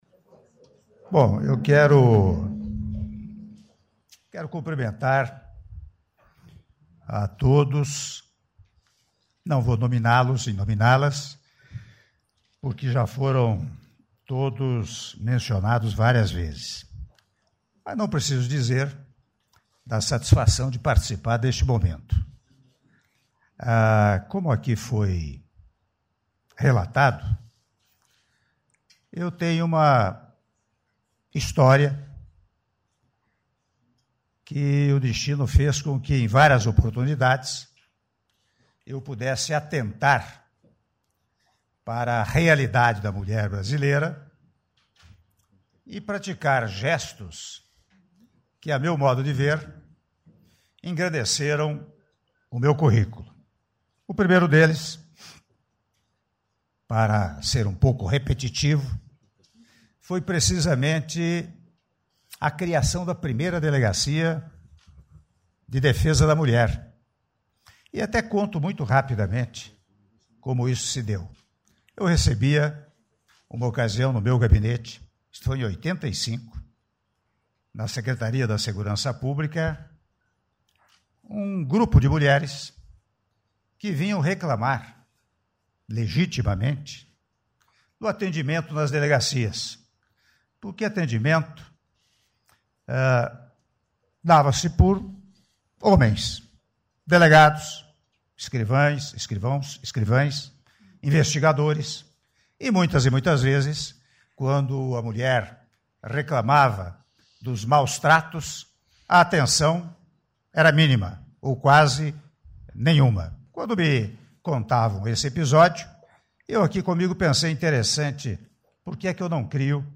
Áudio do discurso do Presidente da República, Michel Temer, durante assinatura do decreto de criação da Rede Brasil Mulher - Brasília/DF (07min45s)